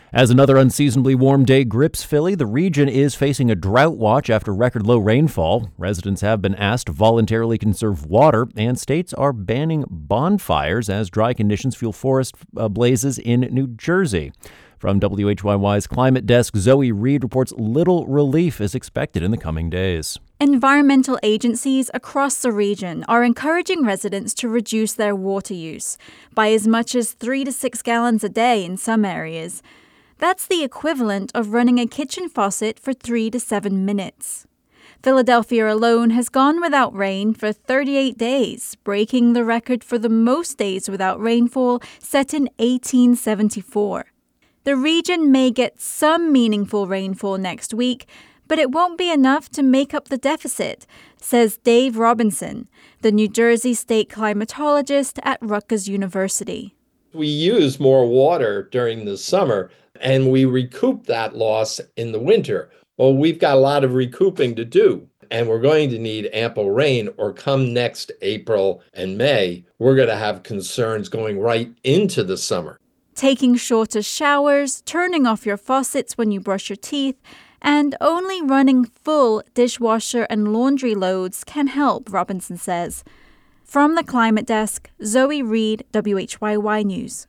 Smyrna and Clayton share a parade and fireworks each fourth of July. The 2011 version featured marching bands, fire trucks, politicians, and lots of other characters as they marched down Main Street in Clayton to the corner of Reed Street.